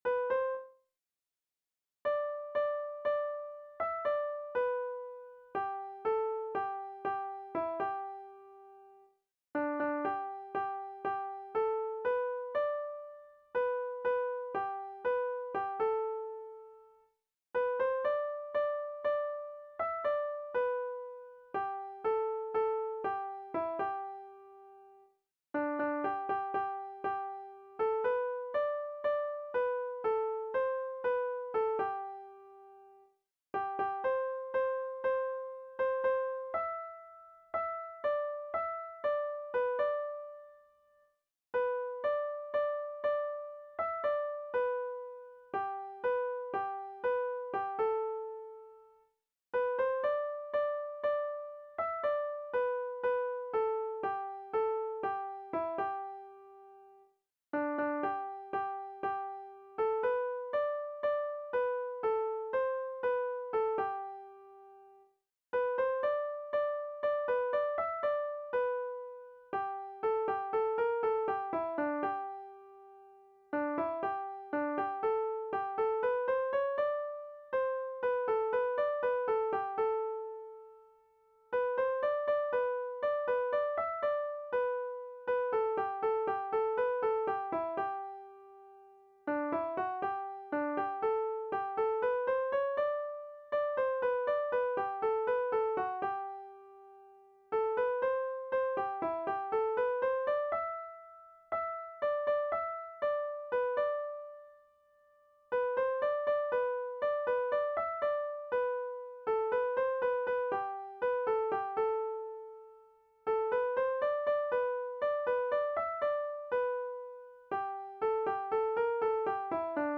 VIOLIN SOLO Violin Solo, Traditional, Fiddling Classic
DIGITAL SHEET MUSIC - VIOLIN SOLO